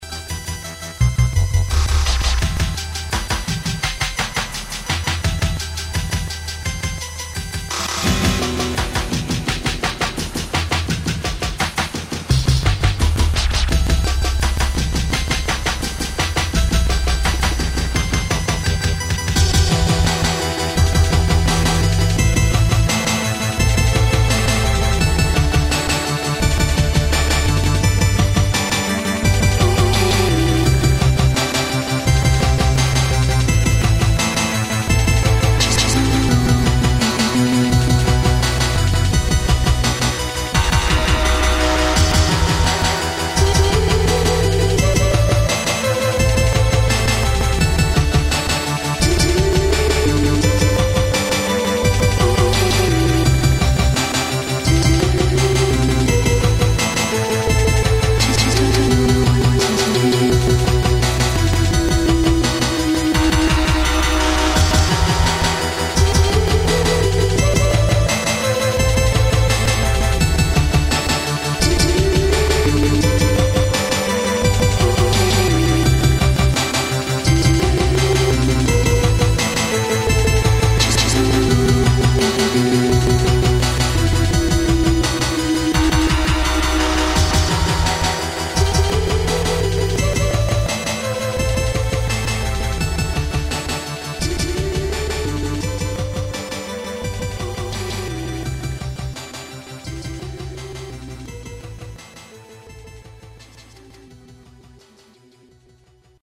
• Качество: 128, Stereo
электронные